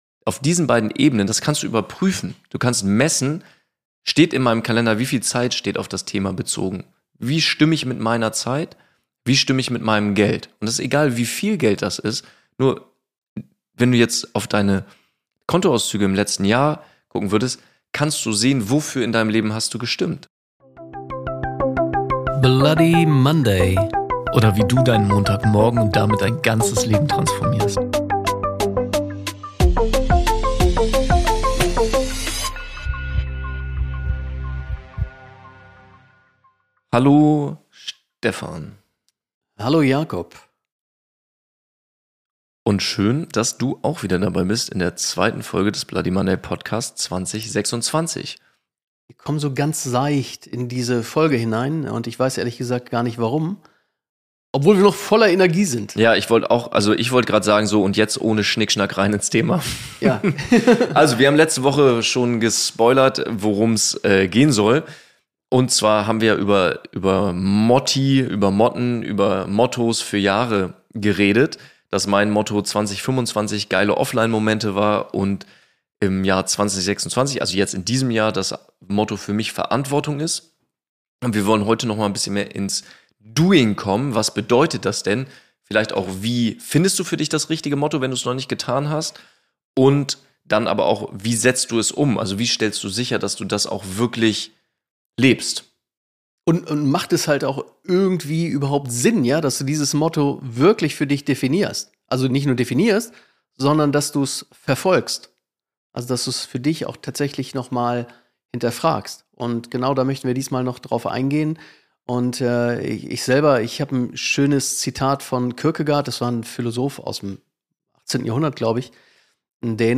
Anhand persönlicher Beispiele, einer geführten Mini-Visualisierung und klarer Bilder zeigen die beiden, wie du deinem Jahr (und deinem Alltag) eine echte Richtung gibst – und wie du vermeidest, 12 Monate später wieder zu sagen: „Eigentlich wollte ich…“.